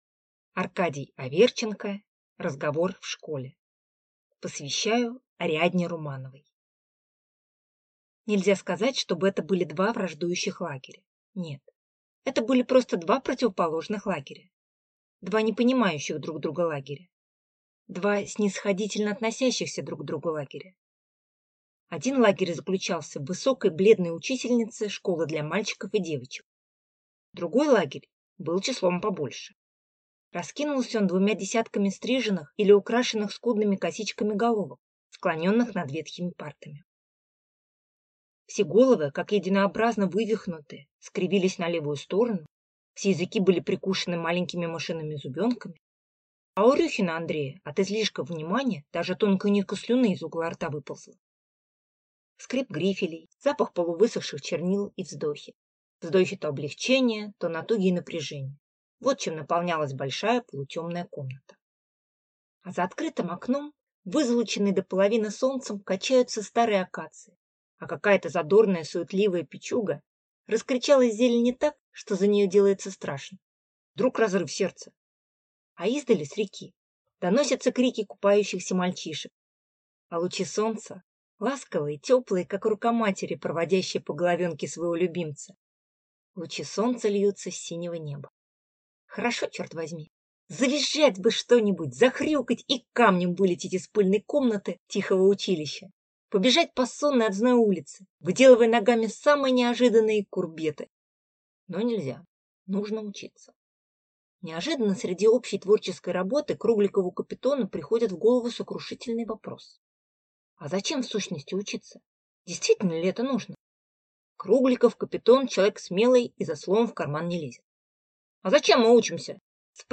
Аудиокнига Разговор в школе | Библиотека аудиокниг